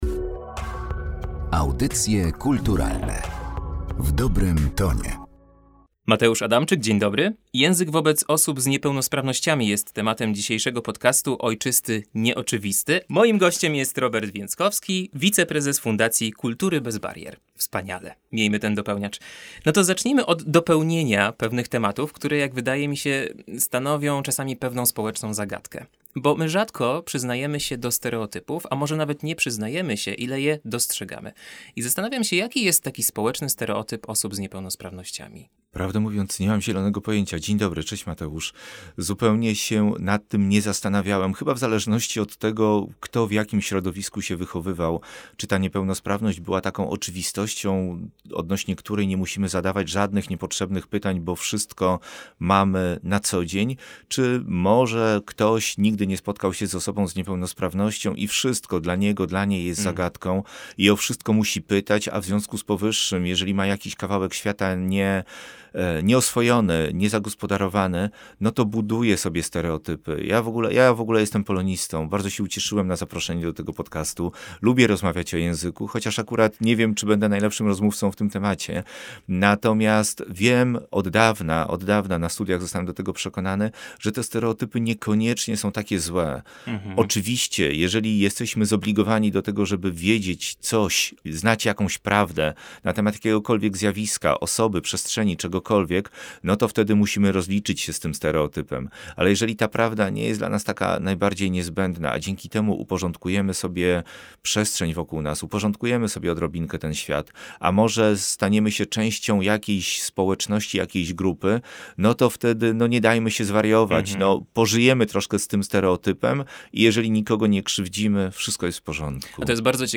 „Ojczysty nieoczywisty” to seria rozmów o języku inkluzywnym, opartym na szacunku i uważności na drugiego człowieka, ale także o wyzwaniach i ograniczeniach naszego języka.